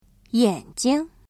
語彙詳細 HOME > 文字検索： y > 眼睛 yǎnjing 文 文法モジュールへリンク 会 会話モジュールへリンク 発 発音モジュールへリンク 眼睛 yǎnjing ※ご利用のブラウザでは再生することができません。 (1)目（め） <例文> 躺着看书对 眼睛 不好。